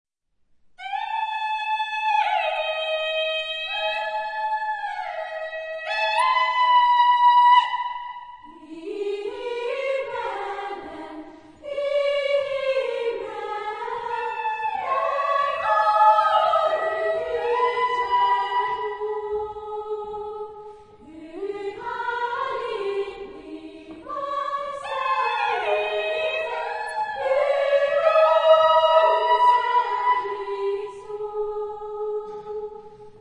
Genre-Style-Forme : Chanson ; Folklore ; Profane
Solistes : Soprane (4)  (4 soliste(s))
Tonalité : la bémol majeur
Consultable sous : 20ème Profane Acappella